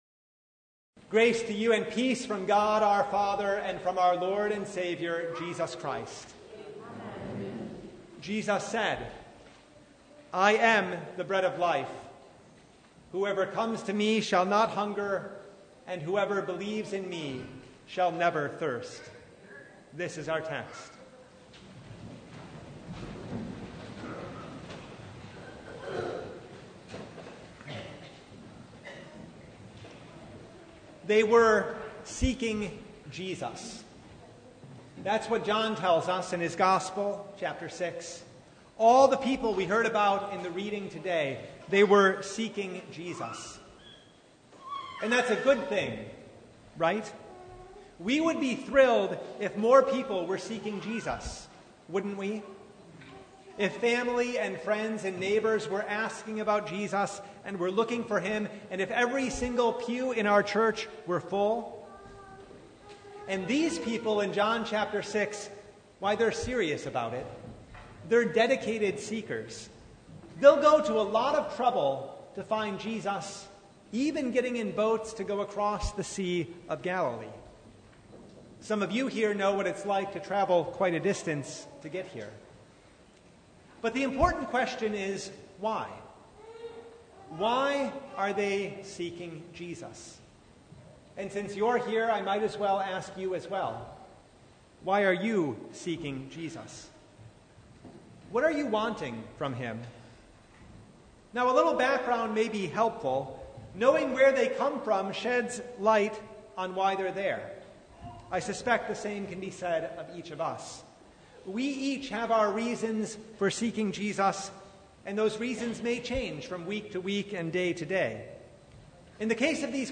Topics: Sermon Only « The Sixth Sunday in Apostles’ Tide What is Baptism?